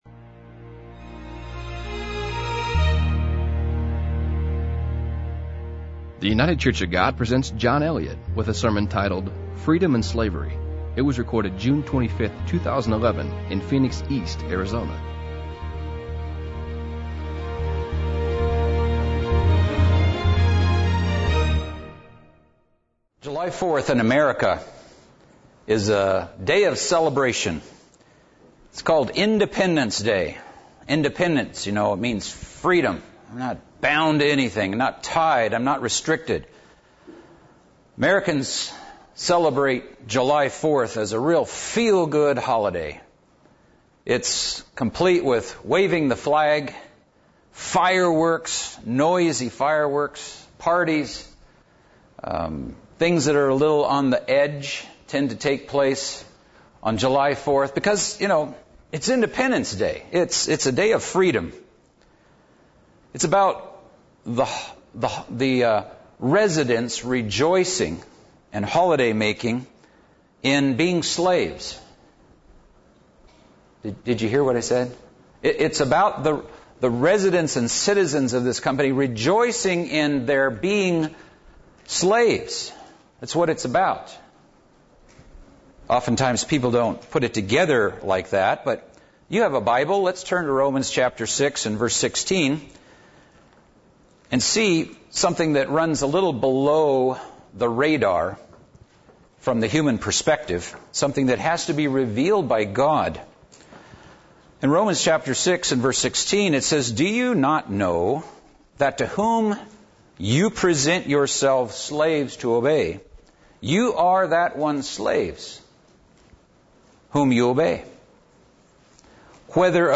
This sermon will analyze, from a very serious perspective, what type or brand of freedom that we celebrate.